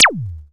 ZAP.wav